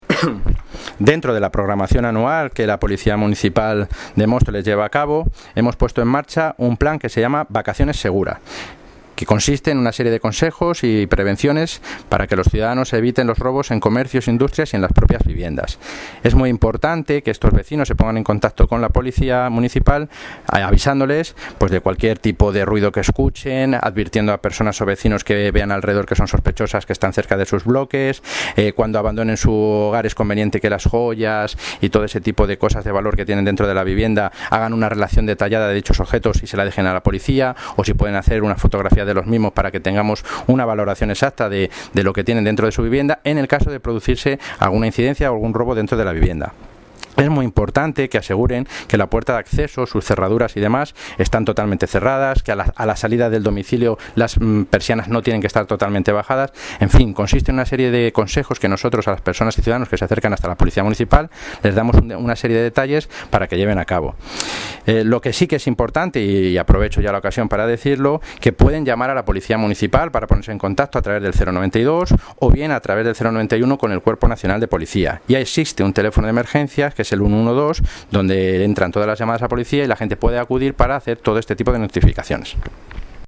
Audio - Roberto Sánchez (Concejal de Presidencia, Seguridad Ciudadana y Movilidad) Sobre Plan de Seguridad para el verano